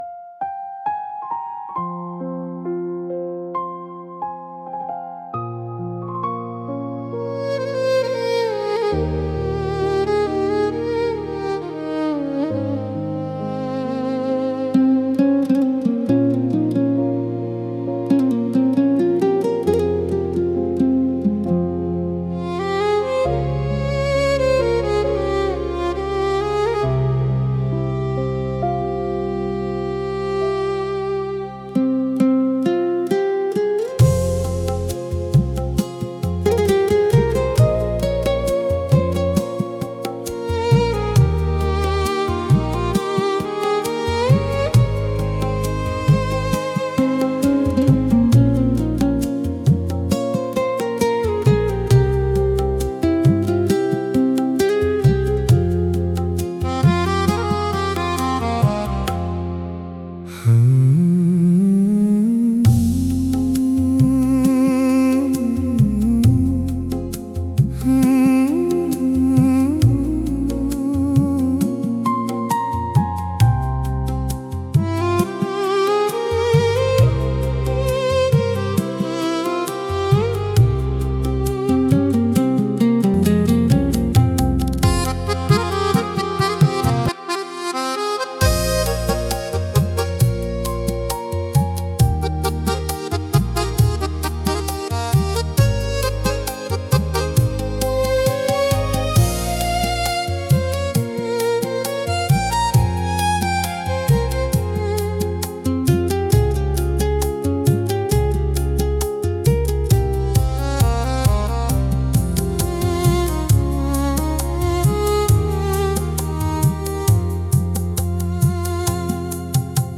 बारिश की रिमझिम 📥 850+ Downloads 00:02:03